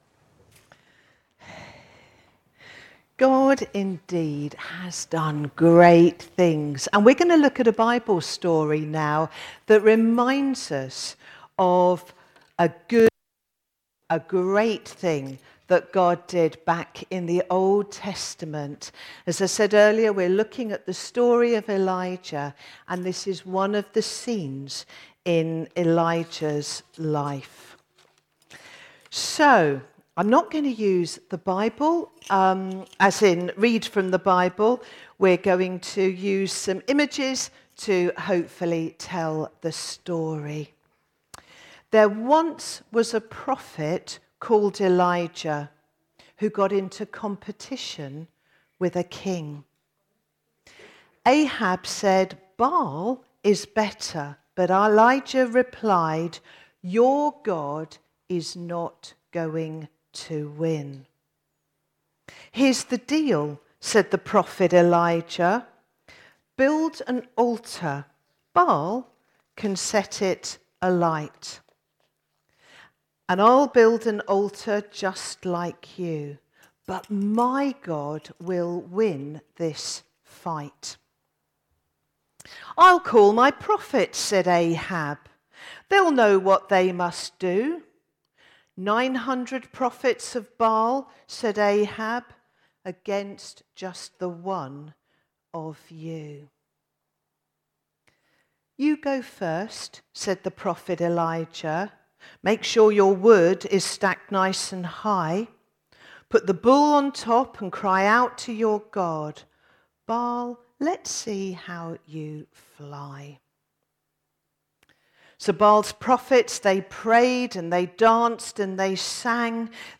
A message from the series "Elijah: Faith and Fire."